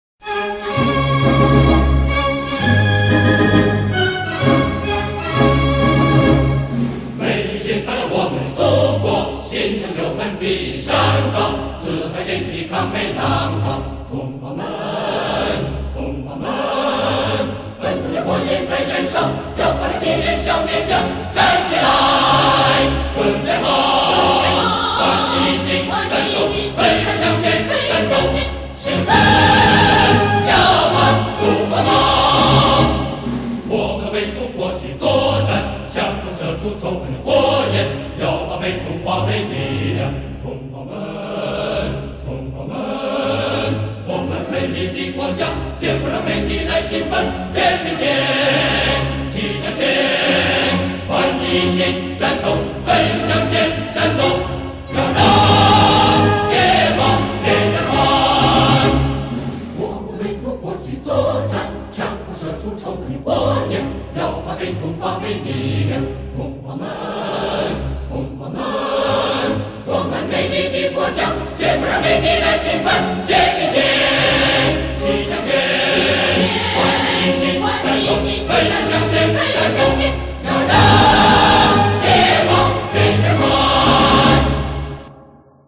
[22/10/2010]越南早期革命歌曲《誓死要把祖国保》